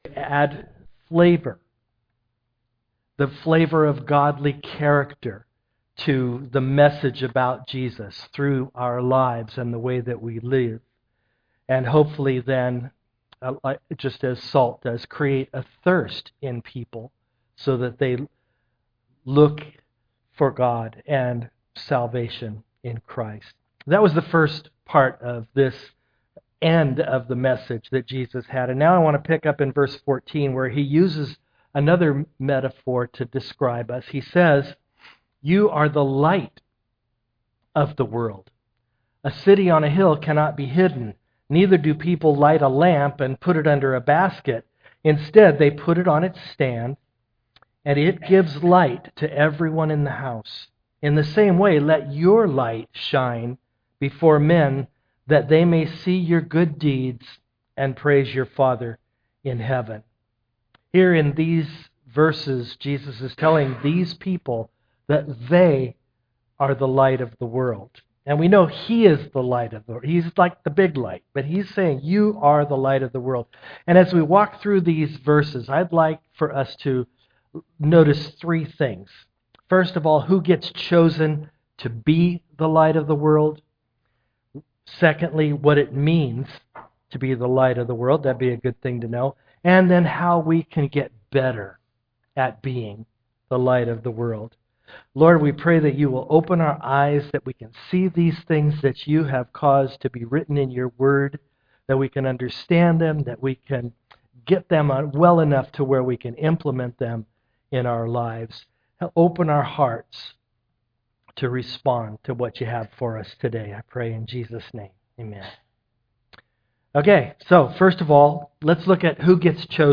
Passage: Matthew 5:14-16 Service Type: am worship